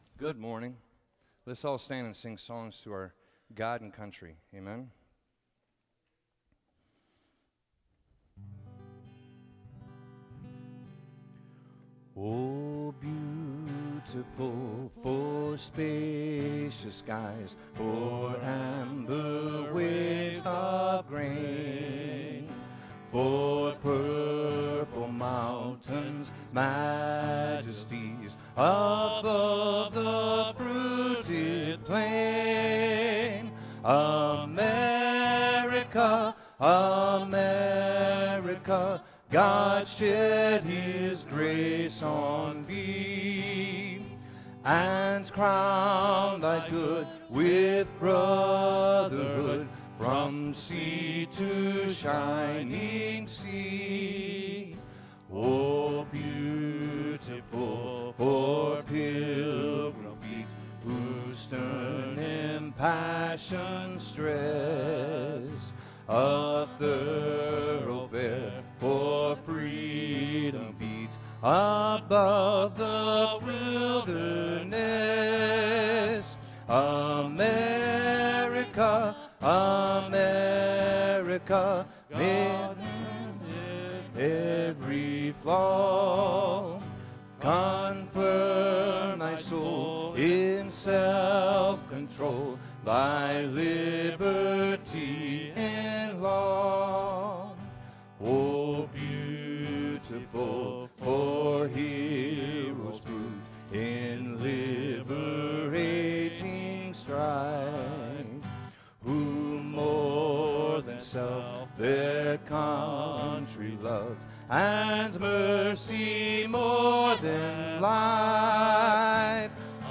Sermons
sermons preached at Grace Baptist Church in Portage, IN